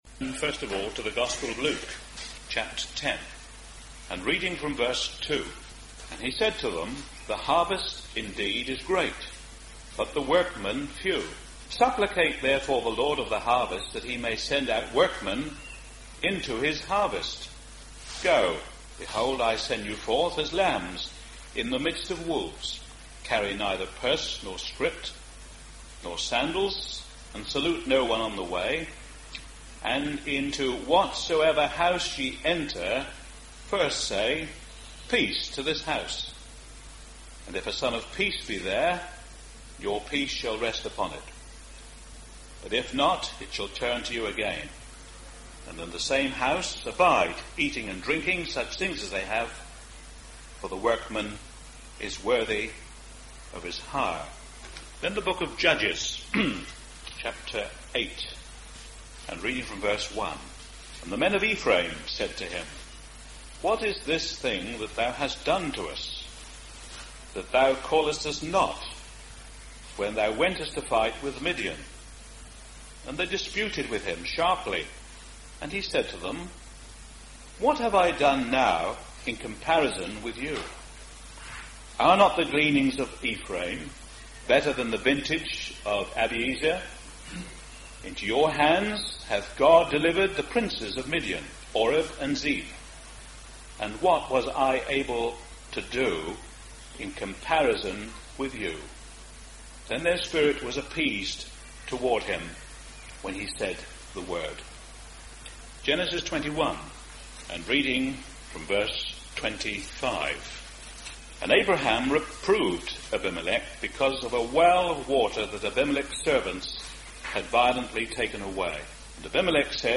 What does it mean to be a Peacemaker? As you listen to this address you will be helped from God’s word to understand how and what a peacemaker really is in the sight of …